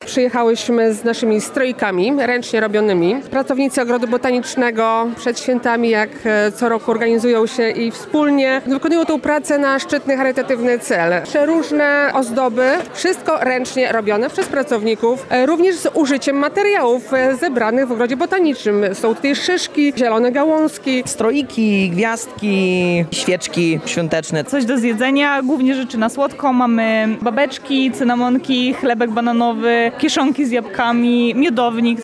Kartki świąteczne, ozdoby choinkowe czy pierniki można nabyć podczas Akademickiego Jarmarku Świątecznego na UMCS w Lublinie. Akcja ma na celu wsparcie fundacji i stowarzyszeń oraz społeczności sąsiedzkich działających na terenie Lublina.